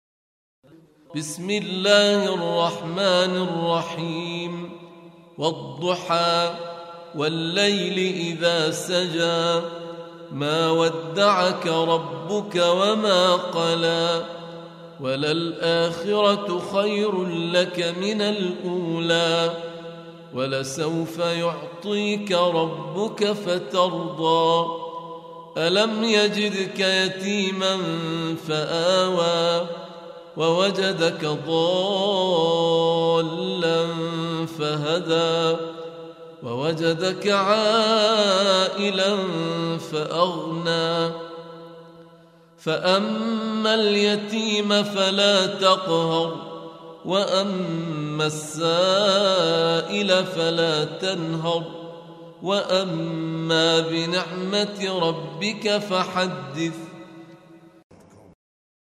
Audio Quran Tarteel Recitation
Surah Sequence تتابع السورة Download Surah حمّل السورة Reciting Murattalah Audio for 93. Surah Ad-Duha سورة الضحى N.B *Surah Includes Al-Basmalah Reciters Sequents تتابع التلاوات Reciters Repeats تكرار التلاوات